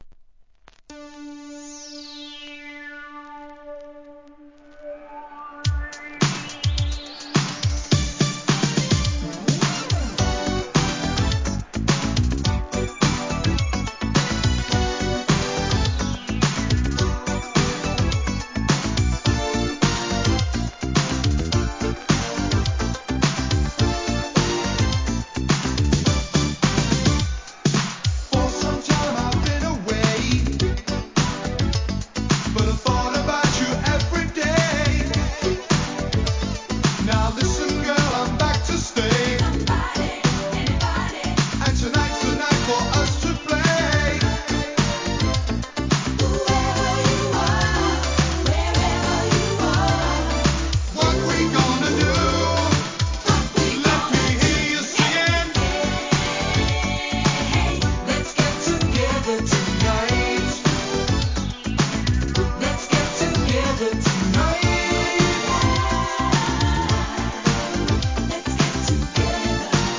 コール&レスポンスで盛り上げます!!